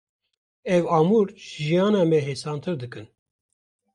Prononcé comme (IPA) /ɑːˈmuːɾ/